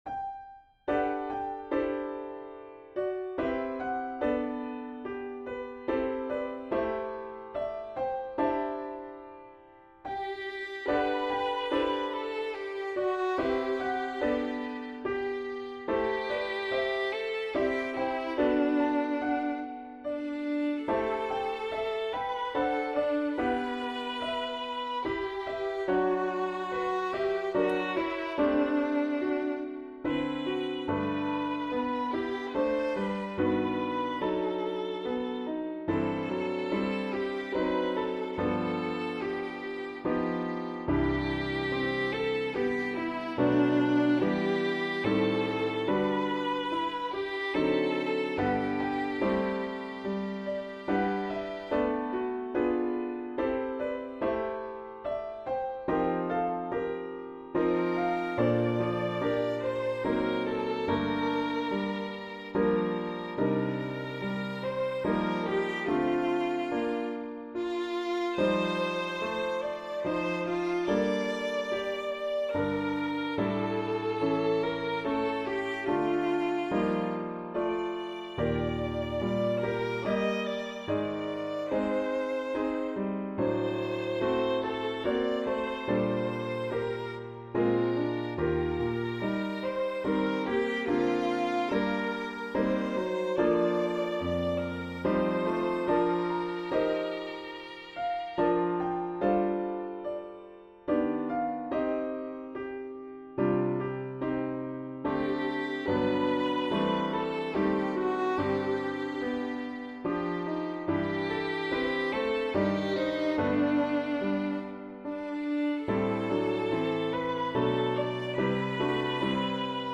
Voicing/Instrumentation: Violin Solo